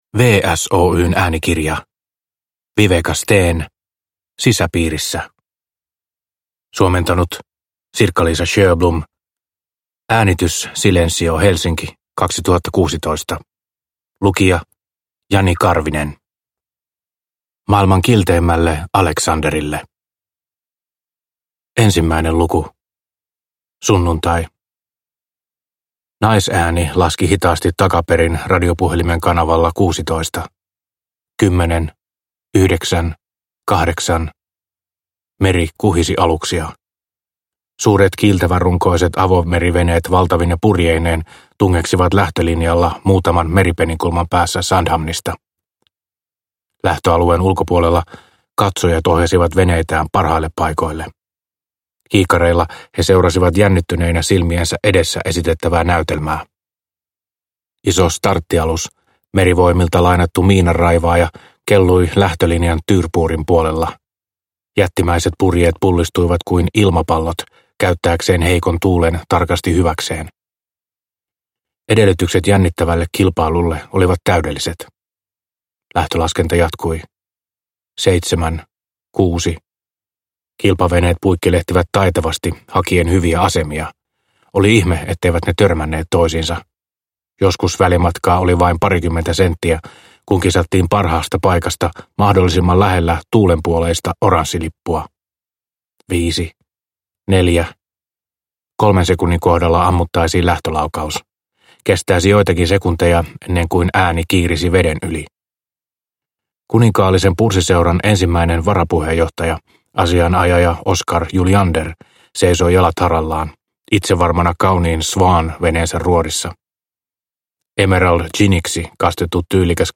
Sisäpiirissä – Ljudbok – Laddas ner